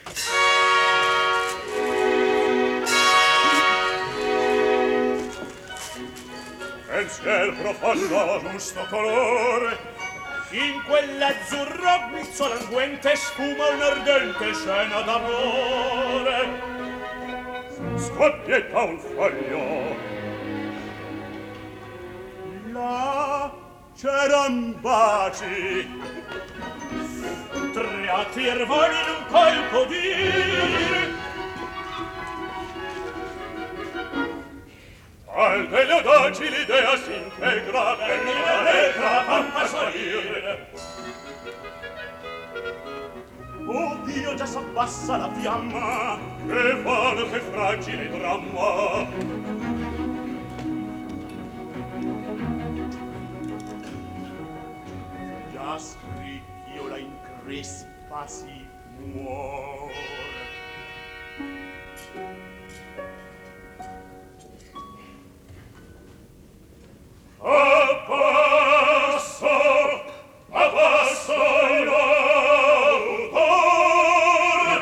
Live performance recorded February 15, 1958
Orchestra and Chorus